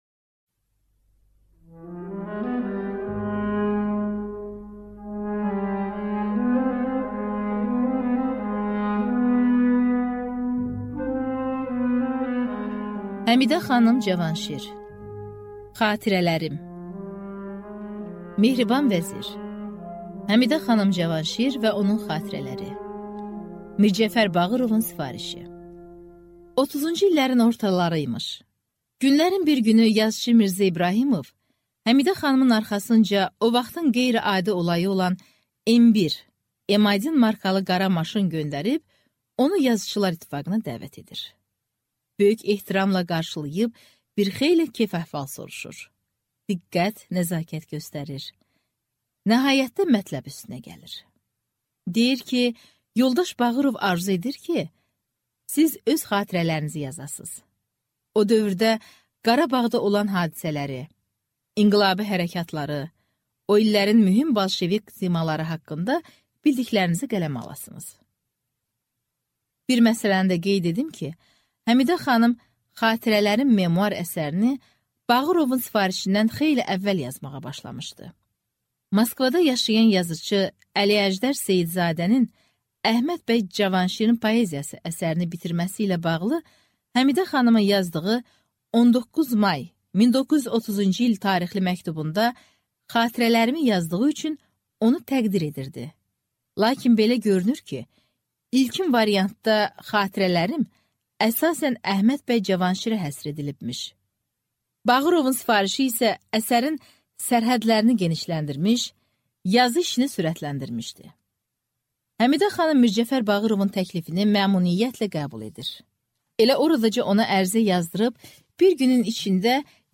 Аудиокнига Xatirələrim | Библиотека аудиокниг